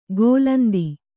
♪ gōlandi